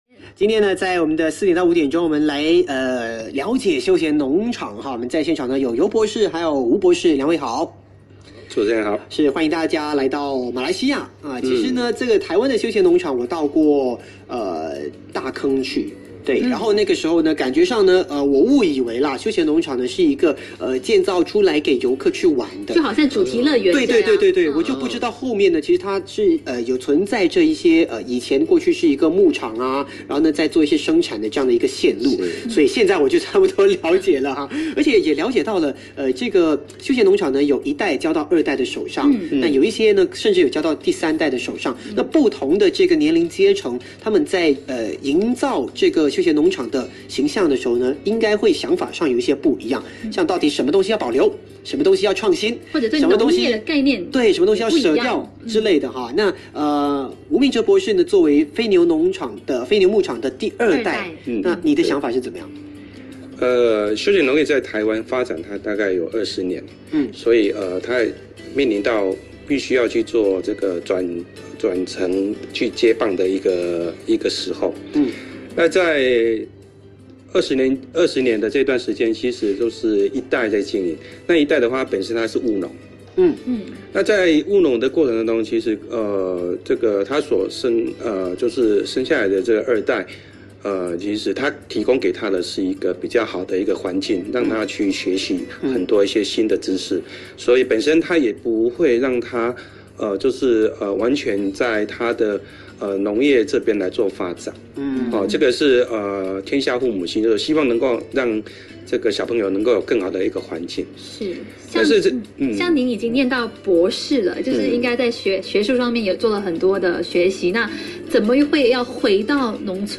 台湾休闲农场 爱FM《爱.四面八方》 LIVE专访